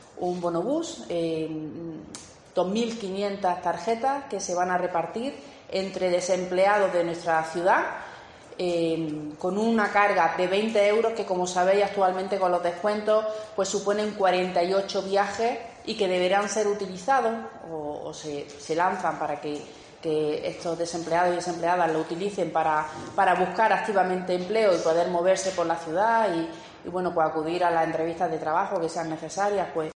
Así lo ha dado a conocer esta mañana la concejala de Protección Ciudadana, Movilidad y Economía y viceportavoz del equipo de gobierno, Raquel Ruz, en comparecencia de prensa ofrecida momentos antes de la celebración de la Junta de Gobierno Local, donde ha destacado el compromiso del gobierno municipal que “ofrece este pequeño gesto para ayudar a las personas, en un momento tan complicado en la vida como es el no tener trabajo, a que consigan un empleo que, sin duda, es un medio de dignificar la vida”.